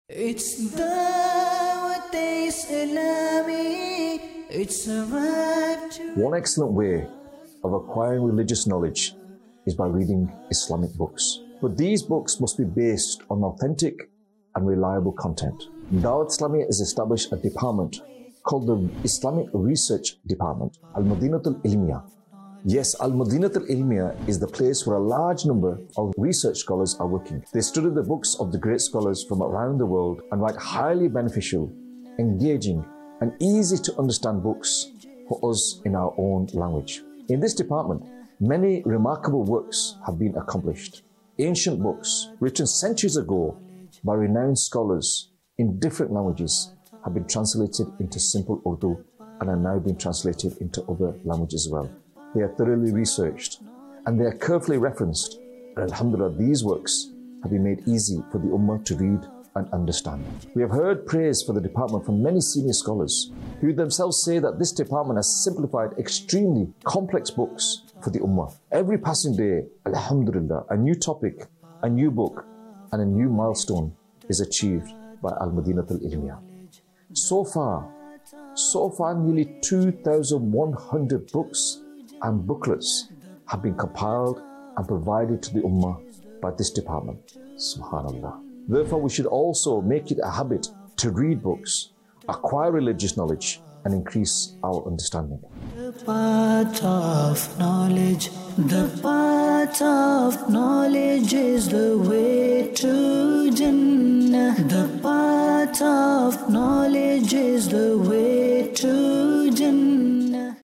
Al-Madinah-Tul-Ilmiah | Department of Dawateislami | Documentary 2026
Vocalist